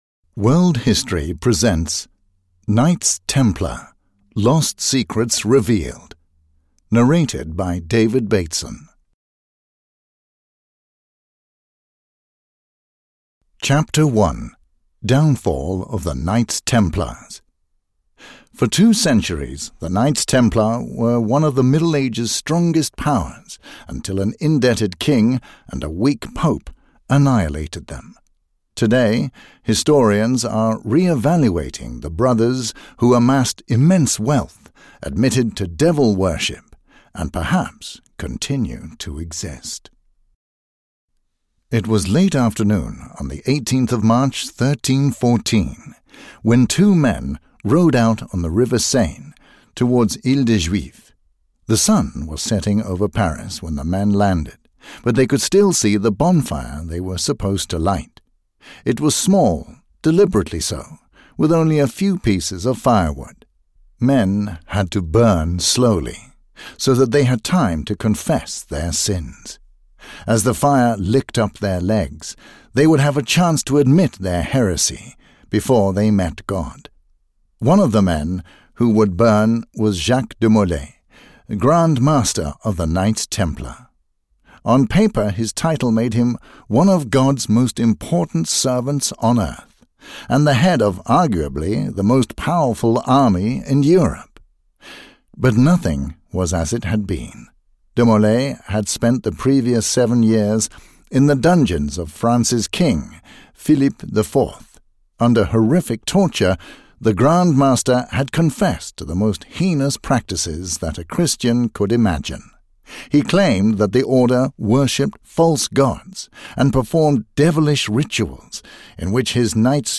Audio knihaKnights Templar: Lost Secrets Revealed (EN)
Ukázka z knihy